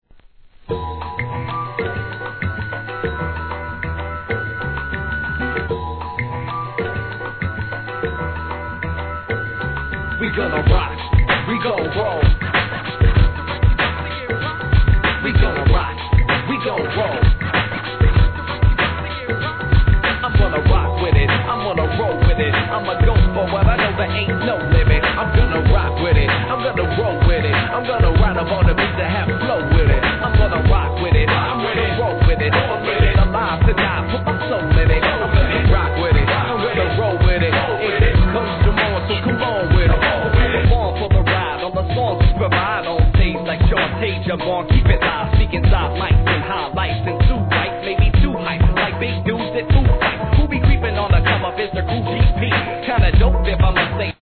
1. HIP HOP/R&B
軽快なJAZZ・ボサBEATの人気曲!!